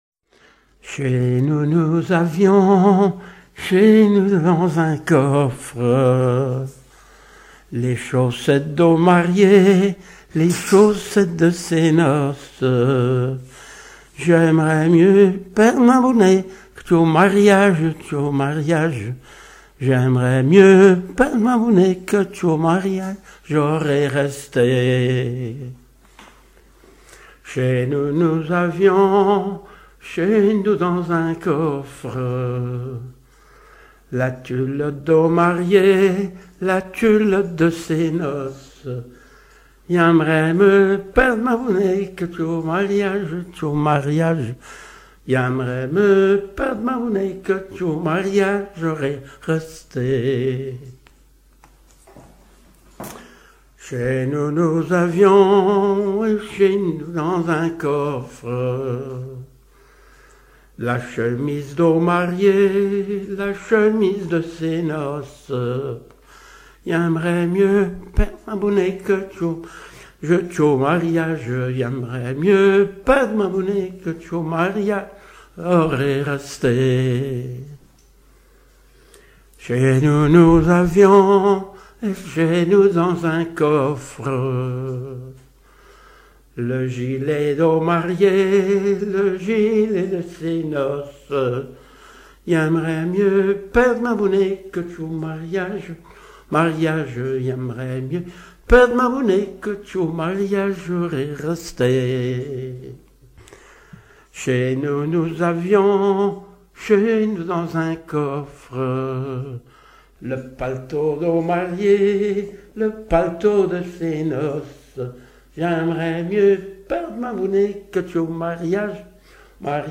chant repris lors des charivaris
Genre énumérative